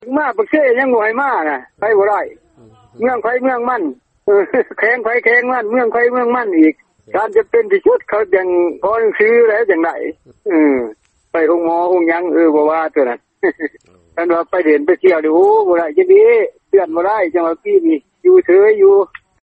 ສຽງສໍາພາດ ປະຊາຊົນ ເຂດເມືອງປາກຊ່ອງ ແຂວງຈຳປາສັກ.